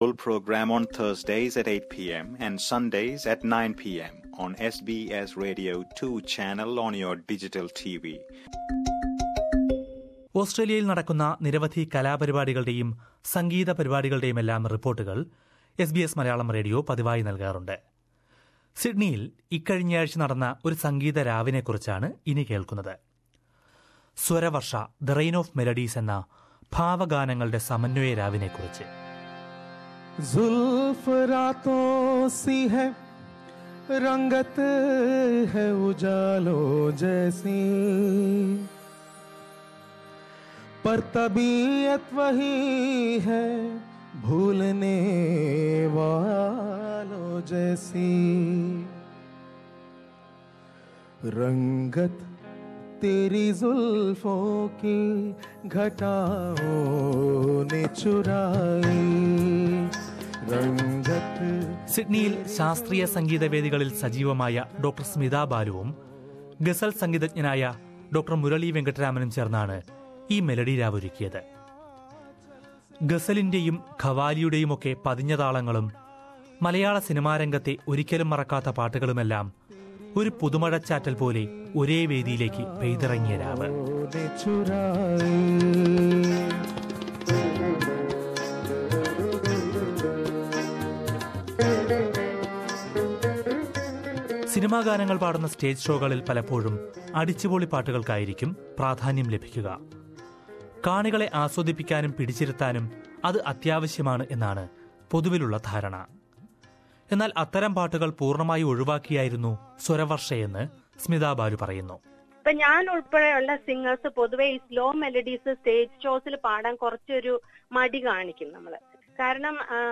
സിഡ്‌നിയിൽ നടന്ന മെലഡി സംഗീത പരിപാടിയാണ് സ്വരവർഷ. വ്യത്യസ്തമാർന്ന മെലഡി ഗാനങ്ങൾ കോർത്തിണക്കി സംഘടിപ്പിച്ച സ്വരവർഷയെക്കുറിച്ചൊരു റിപ്പോർട്ട് കേൾക്കാം മുകളിലെ പ്ലേയറിൽ നിന്ന്...